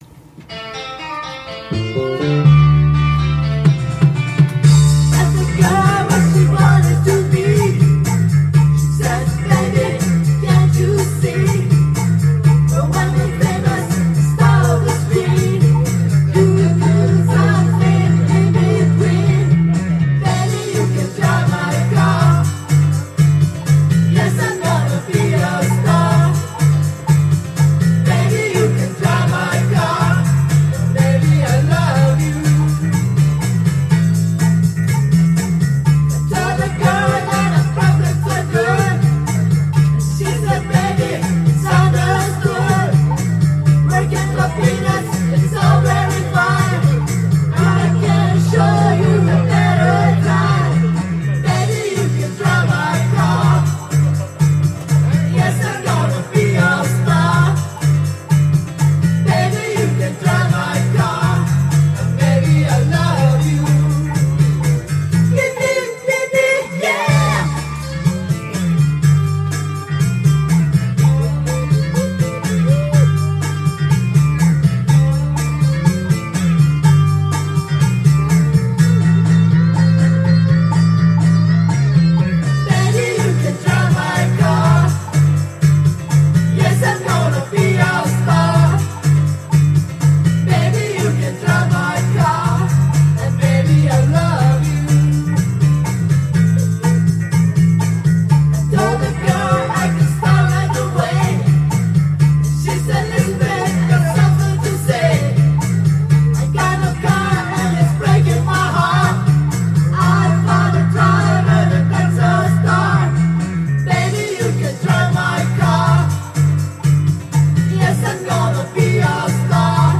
Duet & Chorus Night Vol. 18 TURN TABLE